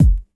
DJP_KICK_ (48).wav